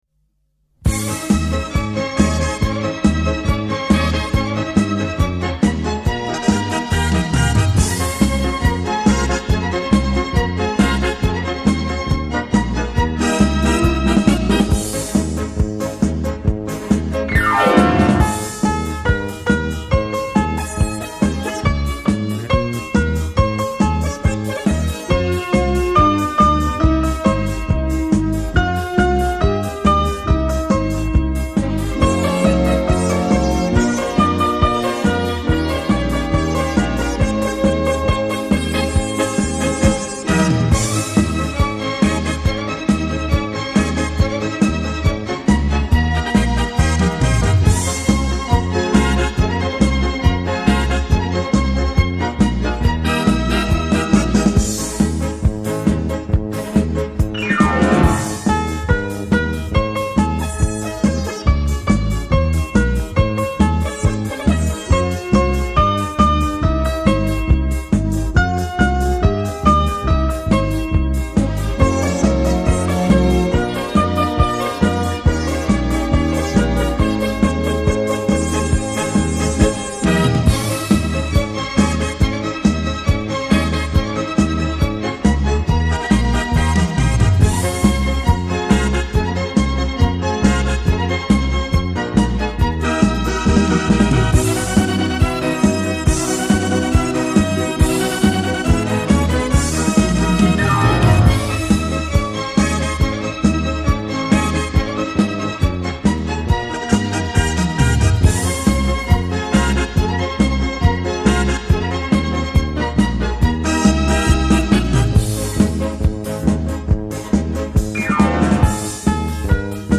快四